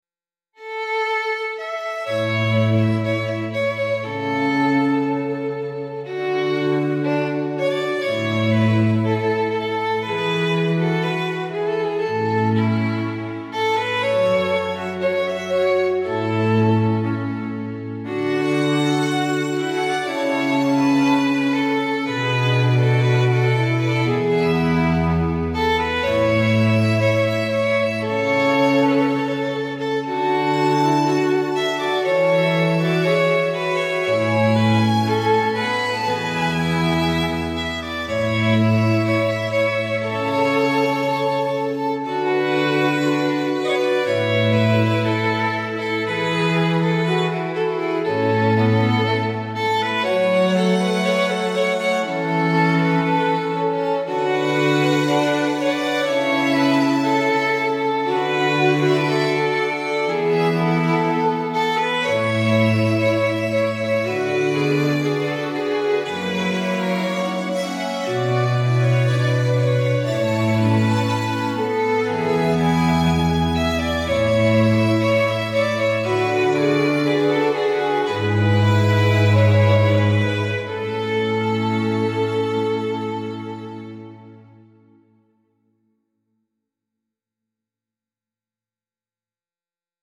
luxurious classical string quartet with refined grace and sophistication